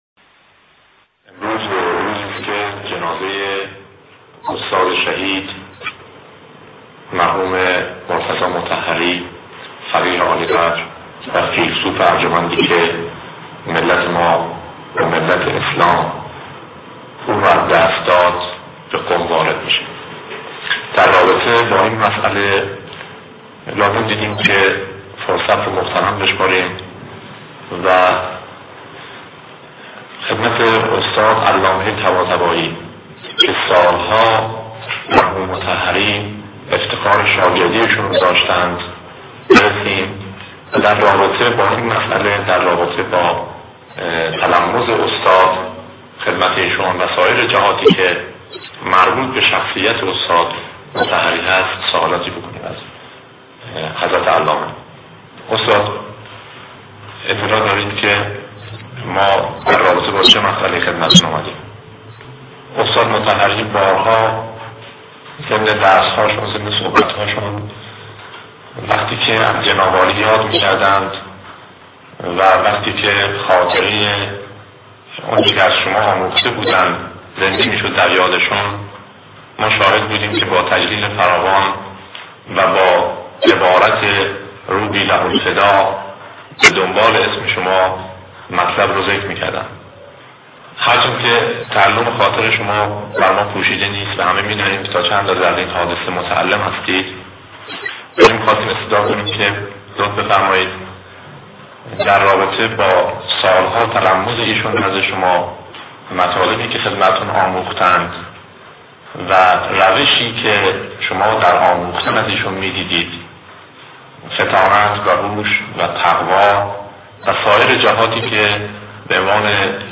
خبرگزاری تسنیم: پایگاه شهید مطهری، گفت‌وگوی منتشرنشده‌ای از علامه طباطبایی بعد از شهادت استاد مطهری منتشر کرده است؛ این گفت‌وگو از طرف رادیو و تلویزیون دولتی ایران در روز تشیع پیکر استاد مطهری در قم انجام شده است.
نکته قابل توجه گریه شدید علامه طباطبایی در زمانی است که مجری از احساس او بعد از شهادت استاد مطهری می‌پرسد.